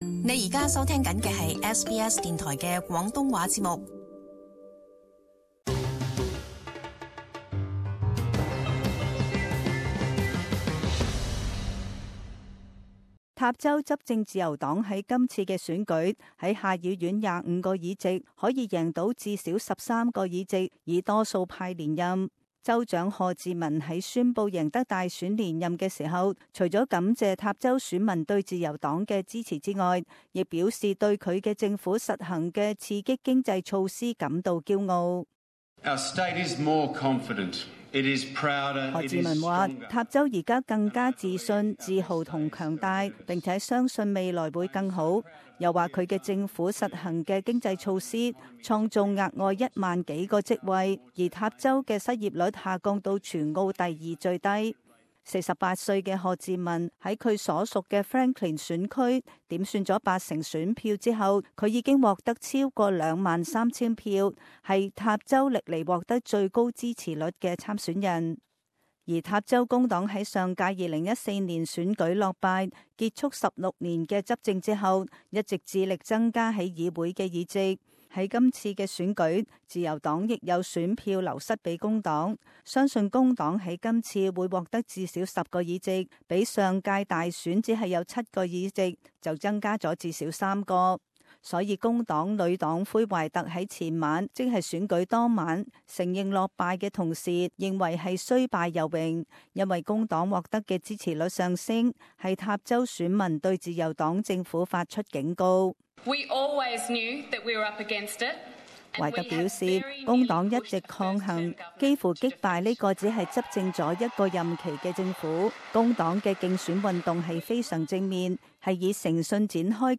【时事报导】塔州大选